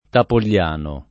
[ tapol’l’ # no ]